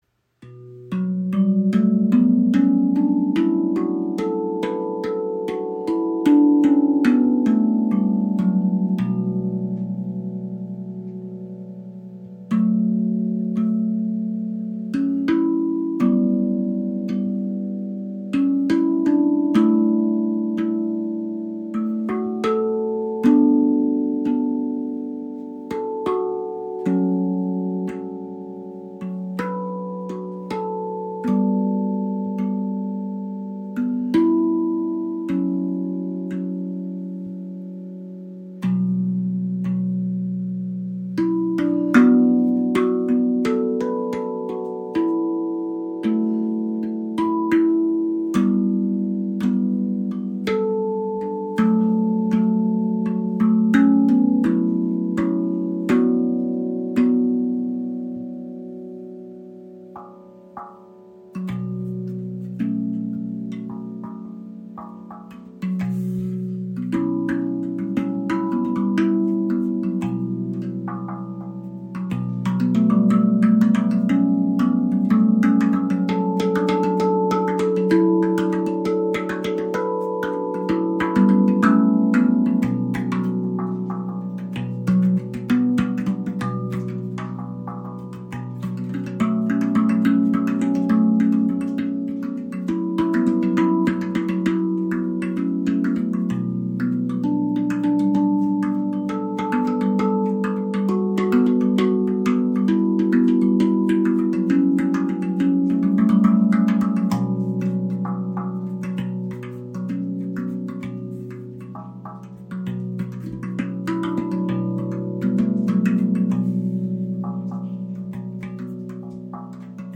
B Kurd 11 | B, F#, G, A, B, C#, D, E, F#, A, B
Erdende und zugleich emotional vielschichtige Moll-Stimmung mit sanfter innerer Spannung. Sie wirkt warm, tief und ausdrucksstark und eignet sich besonders für Meditation, Klangarbeit und therapeutische Anwendungen.